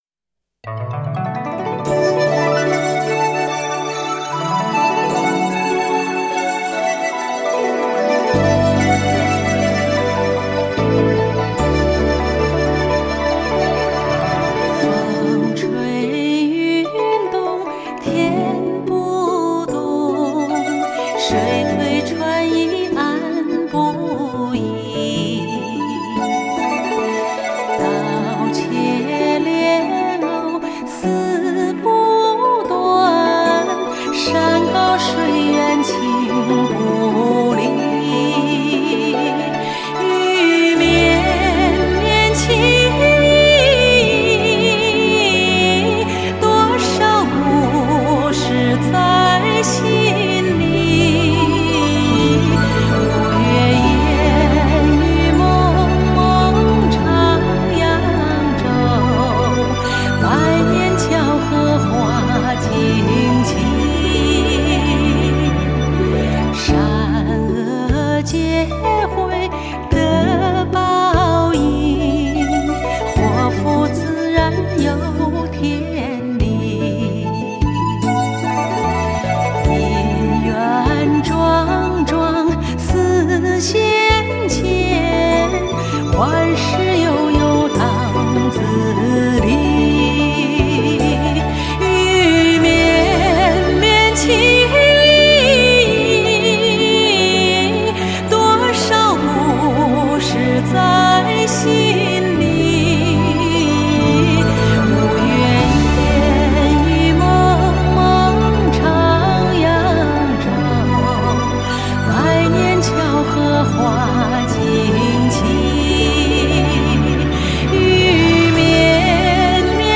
在多部热播的电视剧中，人们听到了同一个磁性美妙的声音，听到了一首首脍炙人口的影视歌曲。